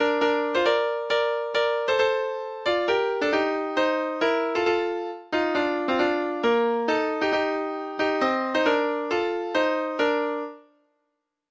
Lytt til data-generert lydfil